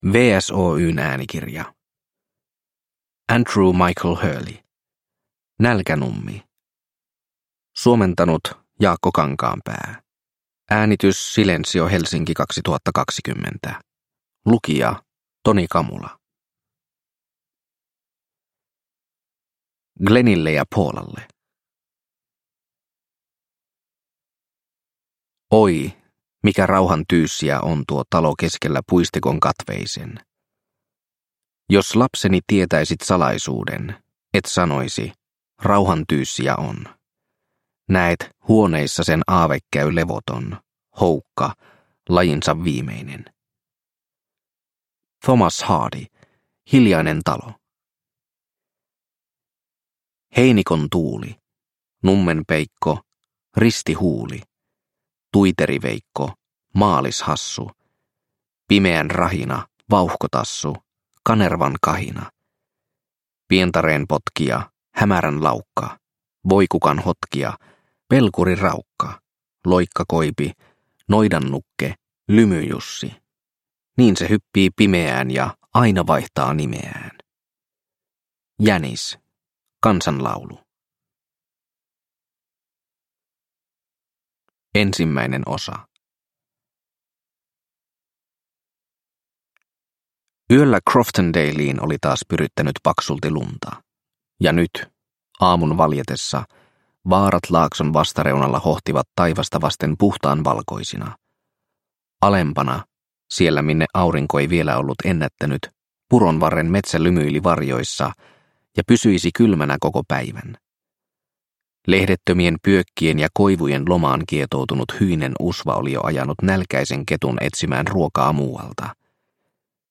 Nälkänummi – Ljudbok – Laddas ner